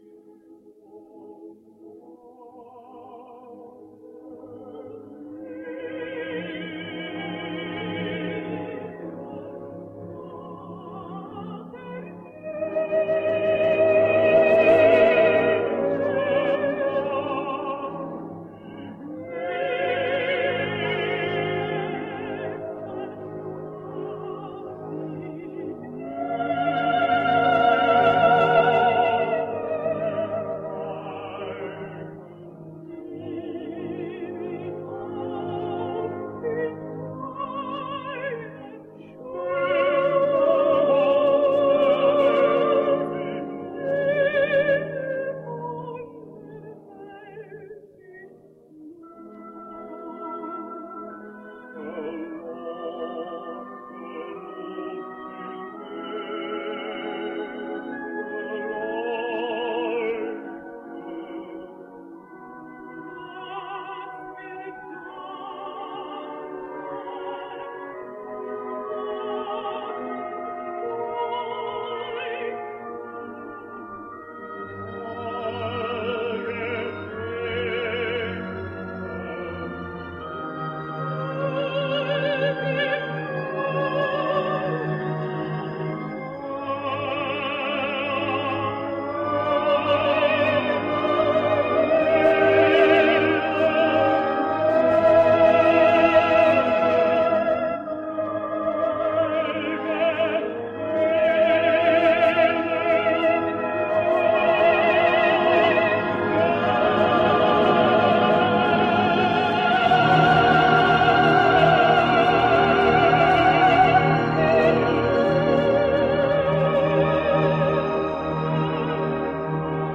Wolfgang Windgassen | French Tenor | 1914 - 1974 | Tenor History